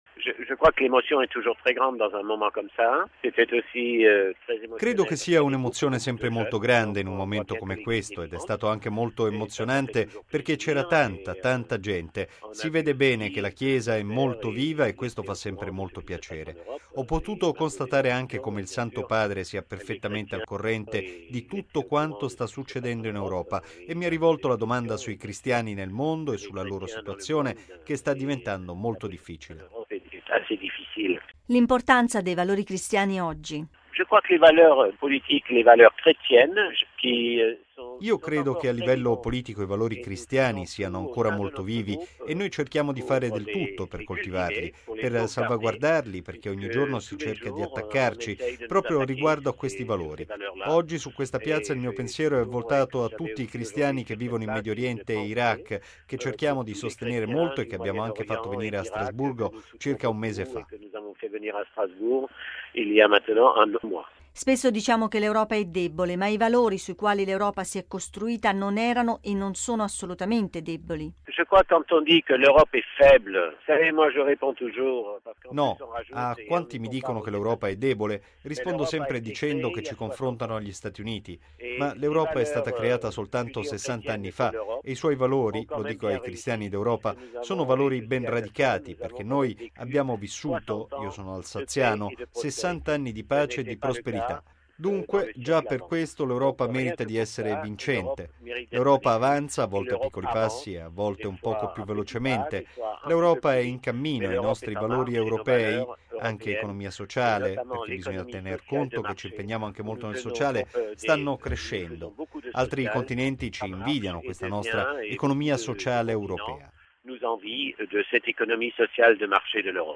◊   Tra i partecipanti all’udienza generale di oggi c’era Joseph Daul presidente del gruppo del Partito Popolare Europeo al Parlamento Europeo.
Nell’intervista